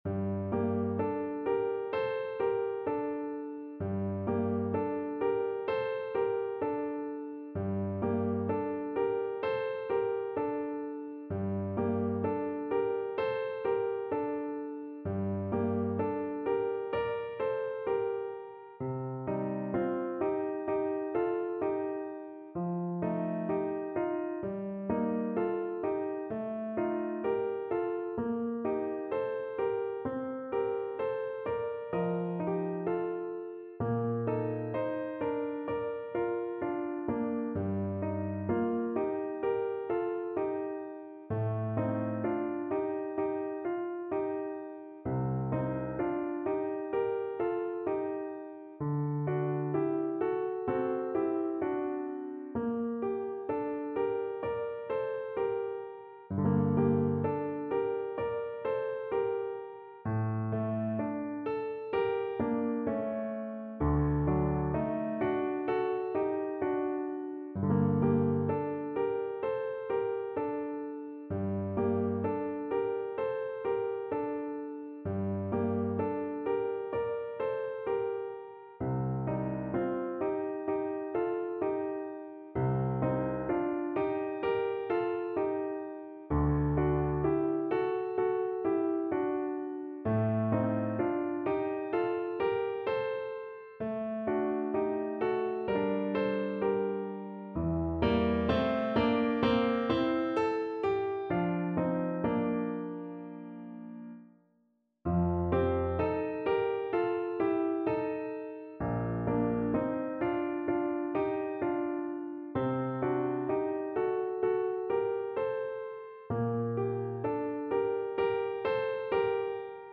Play (or use space bar on your keyboard) Pause Music Playalong - Piano Accompaniment Playalong Band Accompaniment not yet available transpose reset tempo print settings full screen
Flute
G minor (Sounding Pitch) (View more G minor Music for Flute )
Andante sostenuto =32
4/4 (View more 4/4 Music)
Classical (View more Classical Flute Music)